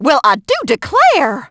piper_hurt_01.wav